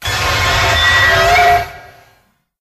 mecha_godzilla_spawn_01.ogg